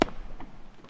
爆炸 " 008 烟花
Tag: 大声 动臂 烟花 爆竹 爆炸